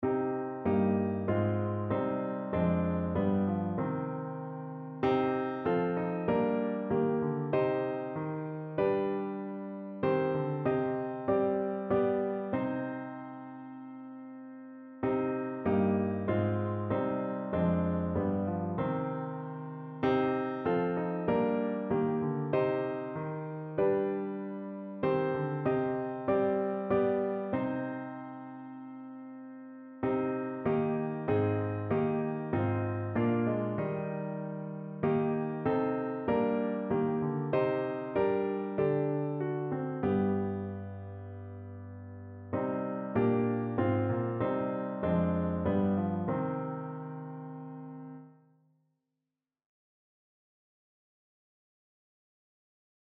Notensatz (4 Stimmen gemischt)